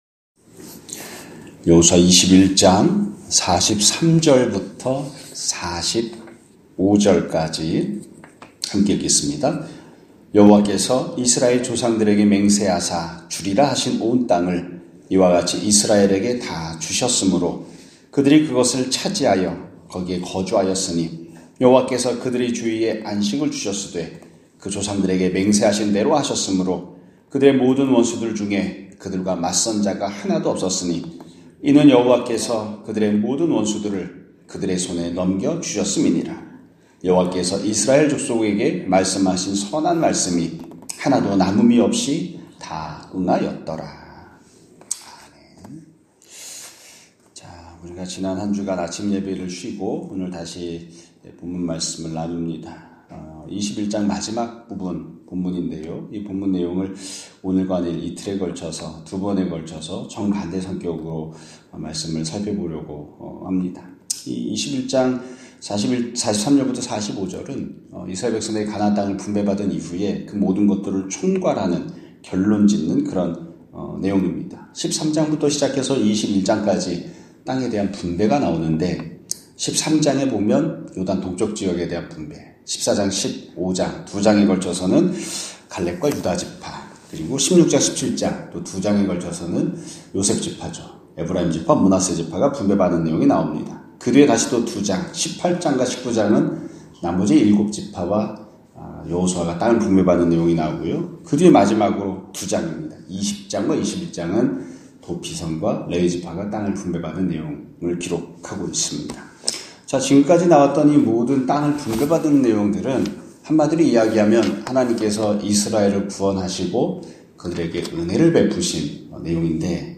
2025년 2월 3일(월 요일) <아침예배> 설교입니다.